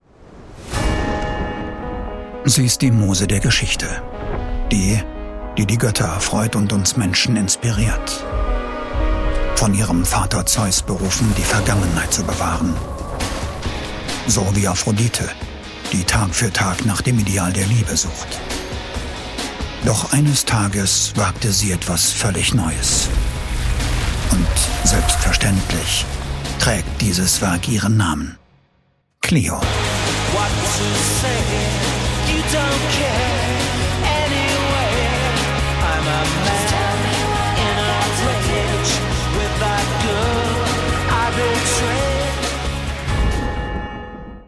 Native speakers